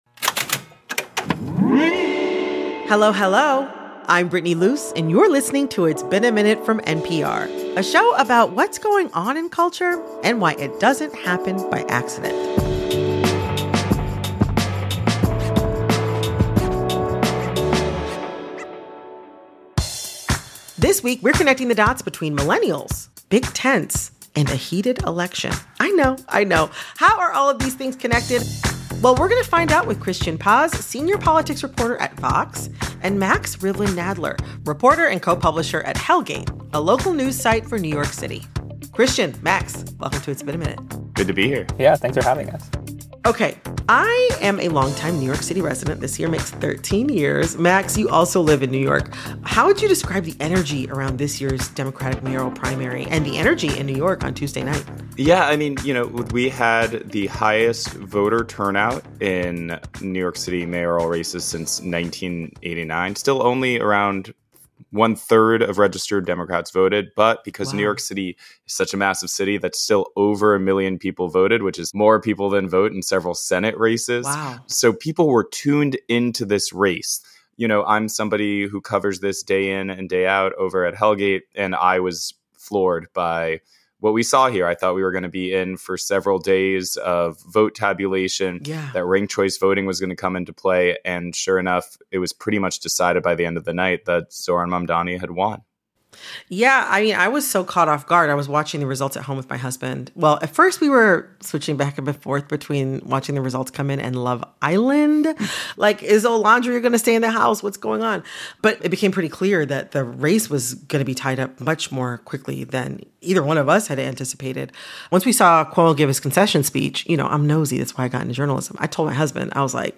Analysis